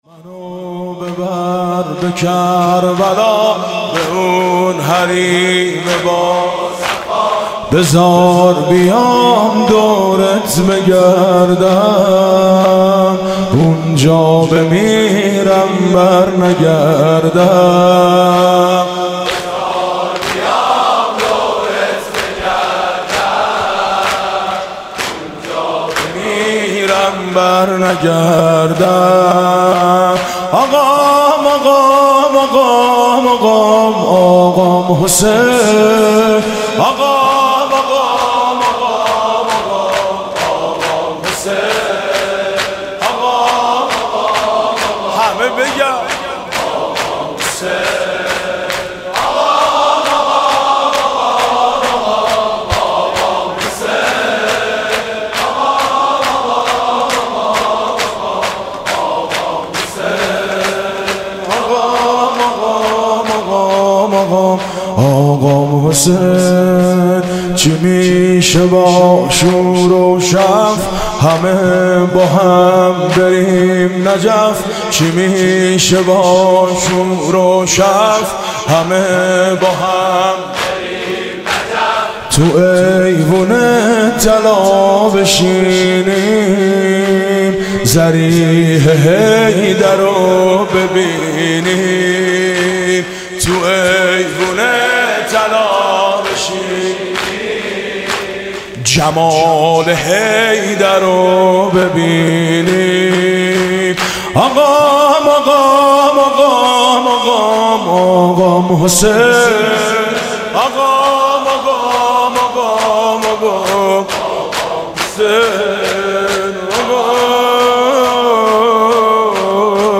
منو ببر به کربلا، به اون حریم باصفا (شور)
[[ فایل فشرده ]] - کل مراسم هفتگی هیئت میثاق با شهدا - مورخ 94/08/10